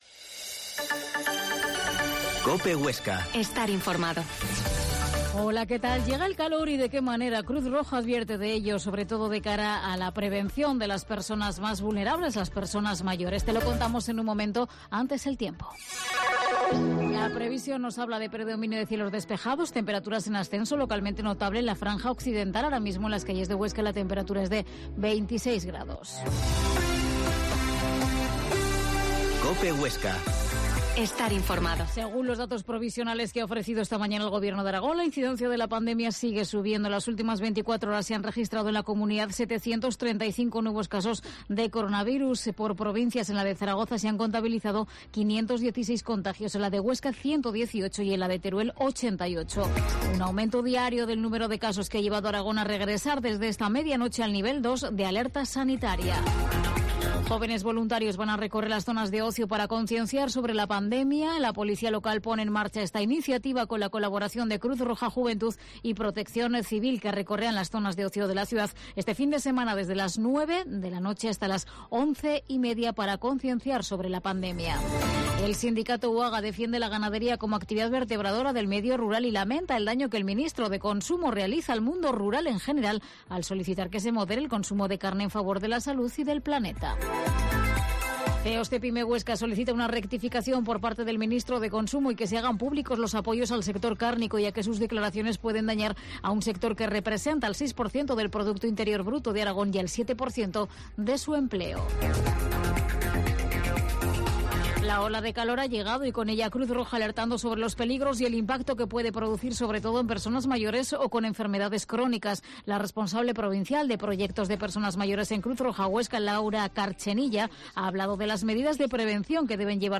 Informativo local Herrera en COPE Huesca